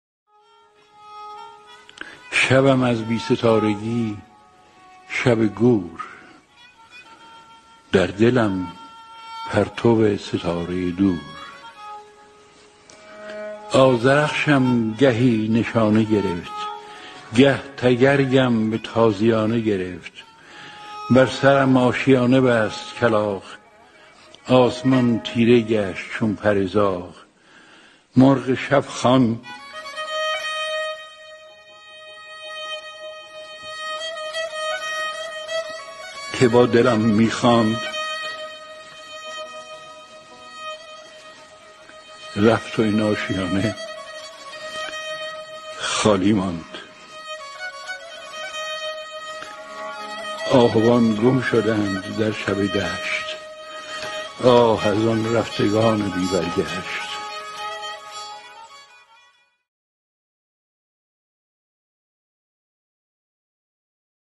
دانلود دکلمه رفتگان بی برگشت با صدای هوشنگ ابتهاج
گوینده :   [هوشنگ ابتهاج]